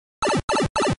mariopipe.mp3